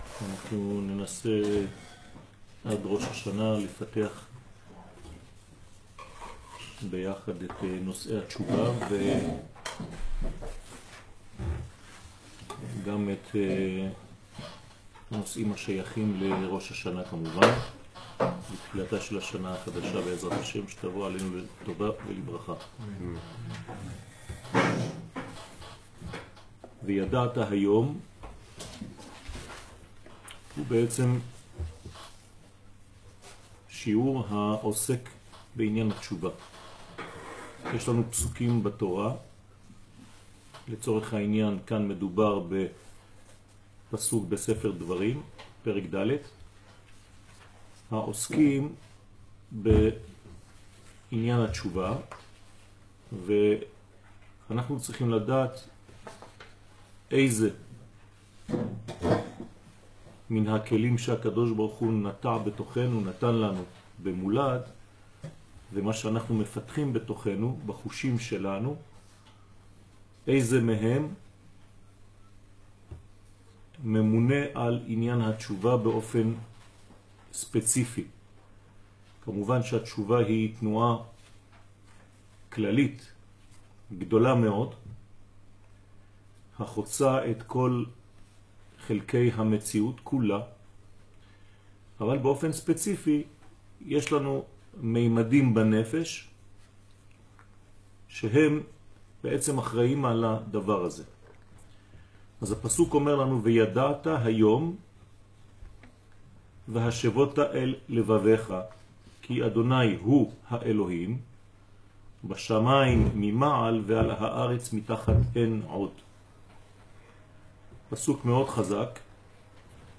שיעור התשובה וראש השנה תשע”ט